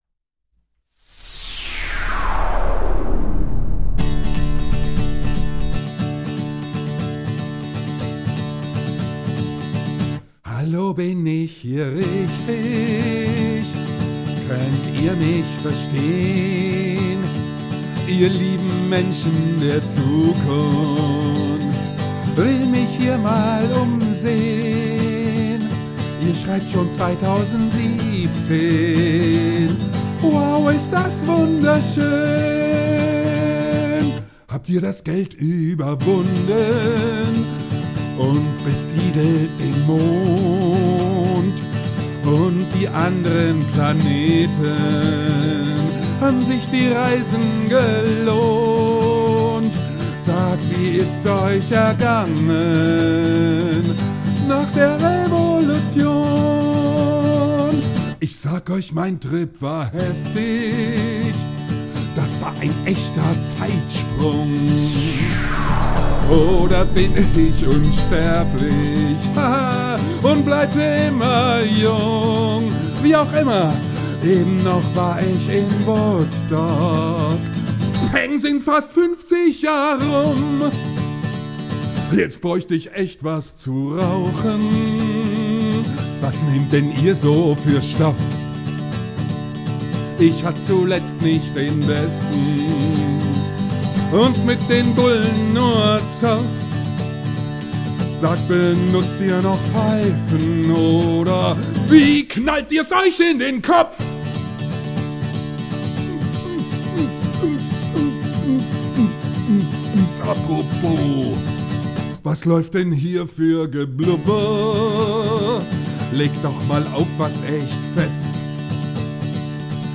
Songskizze für „february album writing month“ FAWM2017: Syn-Geräusch, Gitarre, Gesang